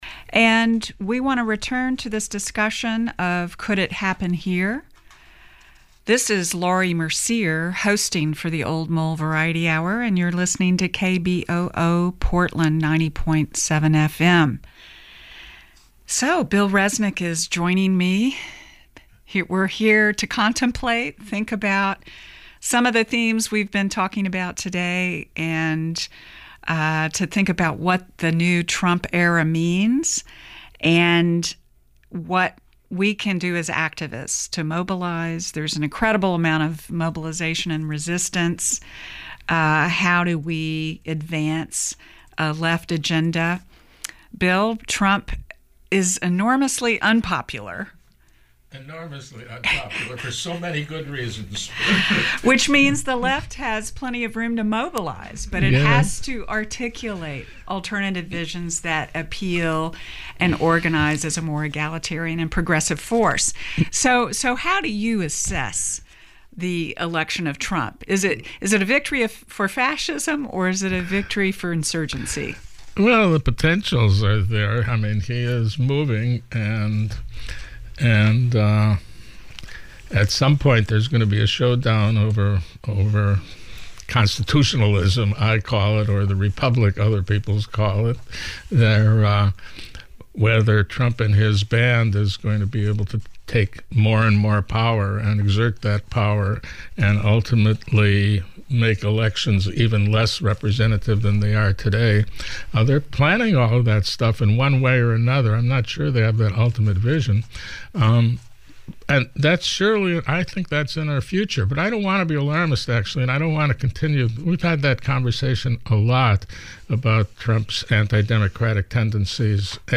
He spoke in April at the Portland Chapter of the Democratic Socialists of America event on Trump and the working c...